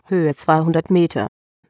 Dazu habe ich mir 100m - 200m - 300m... als Sprachansage bei AT&T erzeugt und die Alarme mit x>100m ... gesetzt.
Wenn du in dem AT&T Link die deutsche Damenstimme nimmst, klingt sie sehr ähnlich.